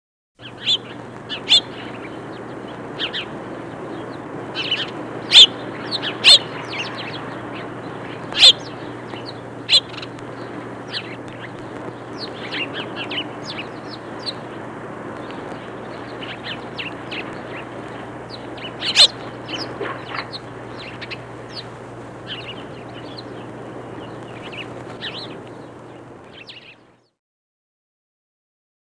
Le moineau | Université populaire de la biosphère
il chuchète, chuchote, pépie, piaille
moineaux.mp3